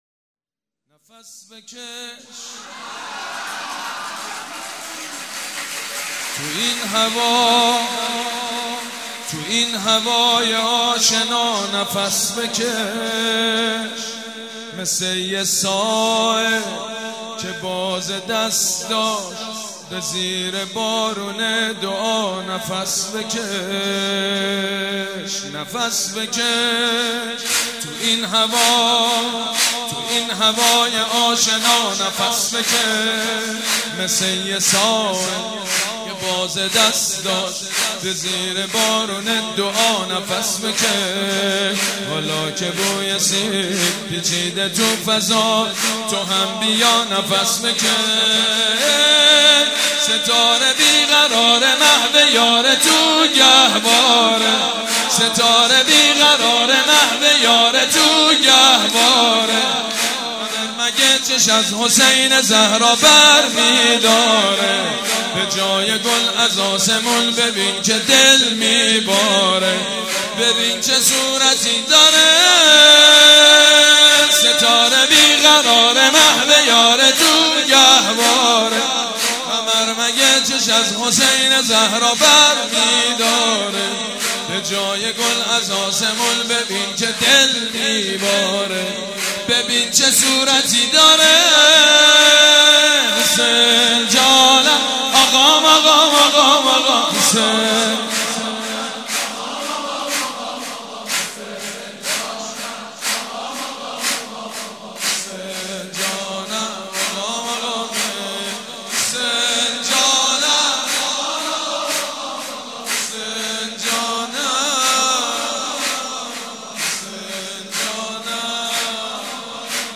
سرود: نفس بکش، تو این هوای آشنا